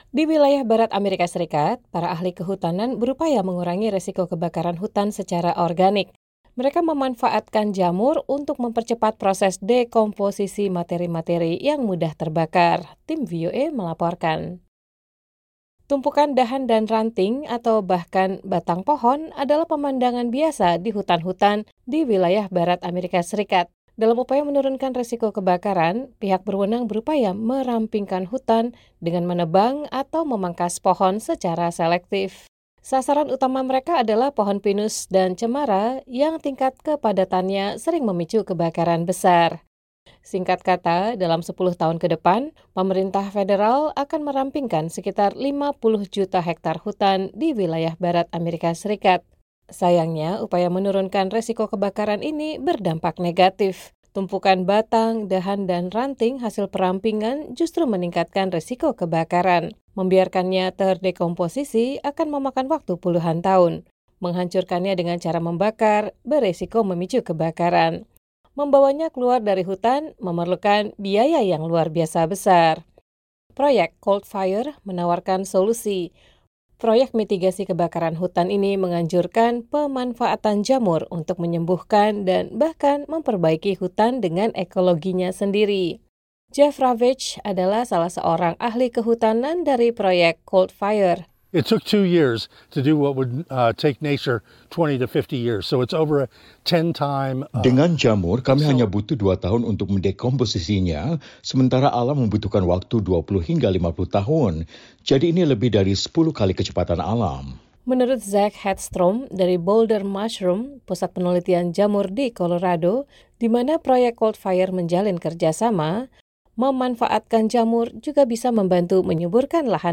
Mereka memanfaatkan jamur untuk mempercepat proses dekomposisi materi-materi yang mudah terbakar. Tim VOA melaporkan.